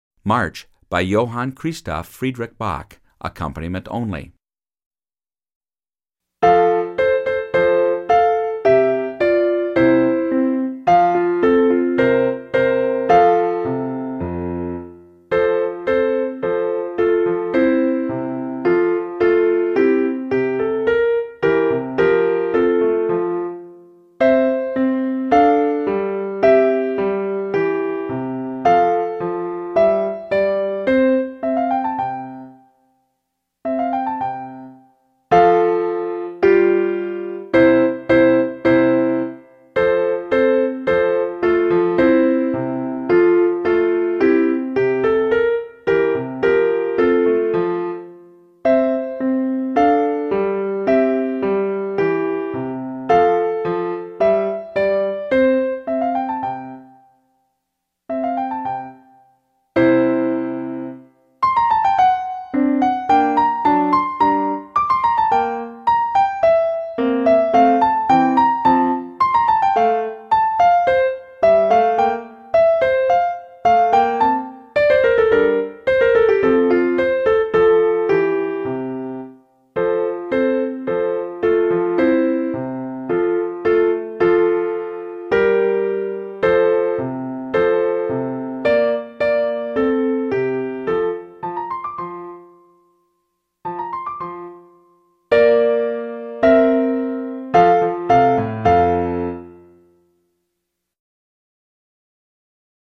March French Horn Accompaniment Only – Performance Tempo
Horn-March-24-Accomp-only.mp3